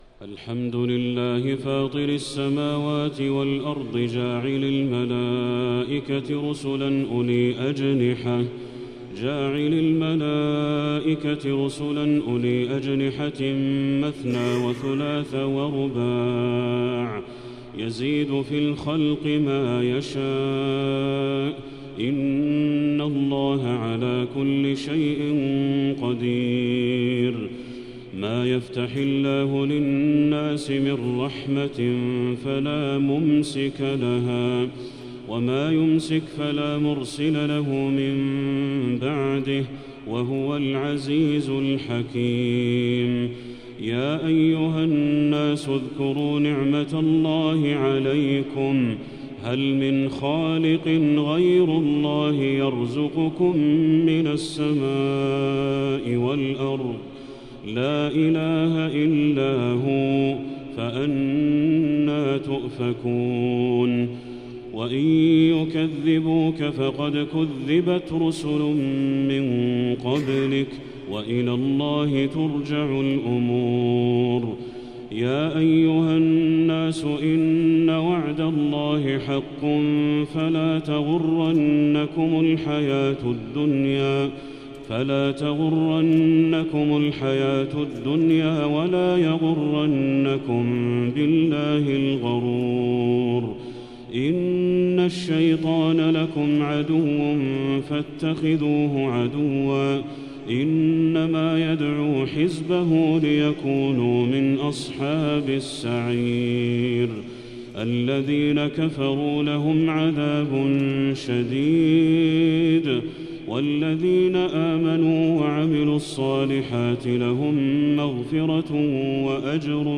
سورة فاطر كاملة | رمضان 1445هـ > السور المكتملة للشيخ بدر التركي من الحرم المكي 🕋 > السور المكتملة 🕋 > المزيد - تلاوات الحرمين